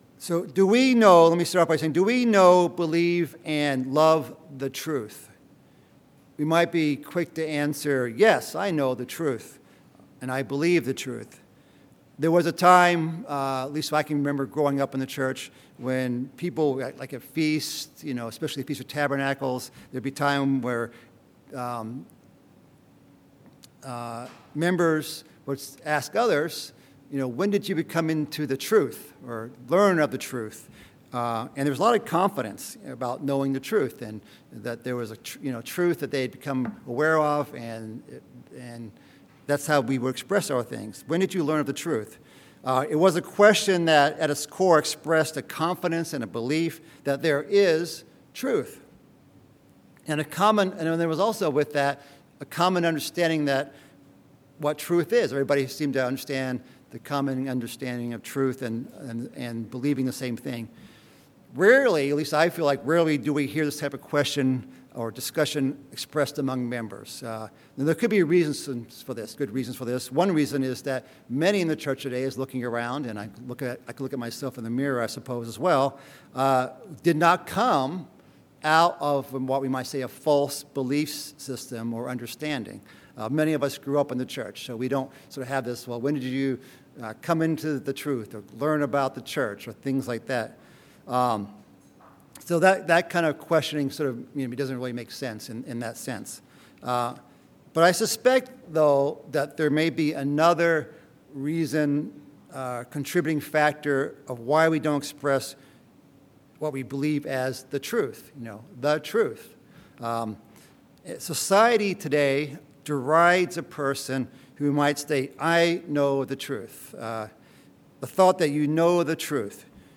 Two Sabbath emails that correspond to this sermon message